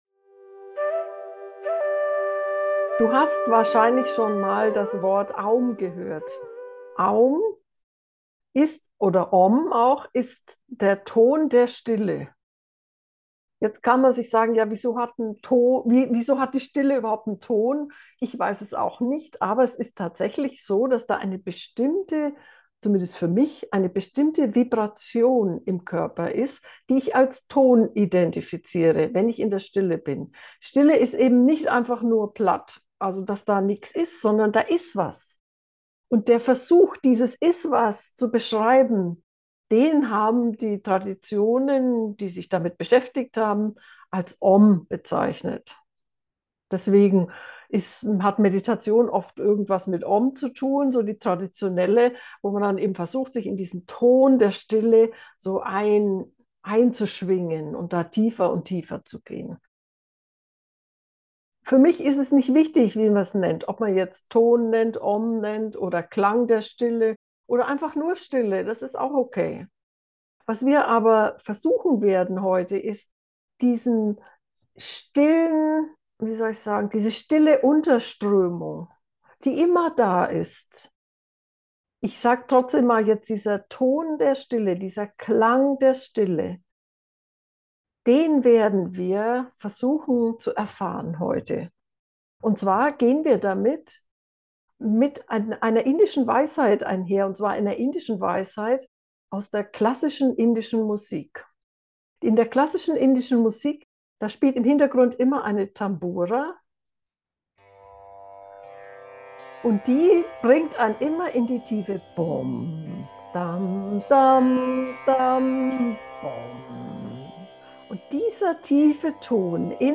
Mit dieser geführten Meditation wirst du feinfühliger und kannst womöglich den Ton der Stille wahrnehmen. Wir nutzen eine indische Tambura als Wegweiser.
stille-ton-gefuehrte-meditation